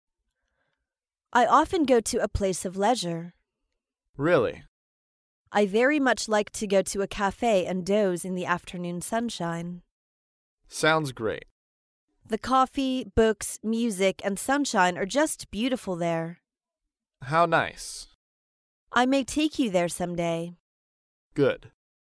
在线英语听力室高频英语口语对话 第196期:谈论休闲生活的听力文件下载,《高频英语口语对话》栏目包含了日常生活中经常使用的英语情景对话，是学习英语口语，能够帮助英语爱好者在听英语对话的过程中，积累英语口语习语知识，提高英语听说水平，并通过栏目中的中英文字幕和音频MP3文件，提高英语语感。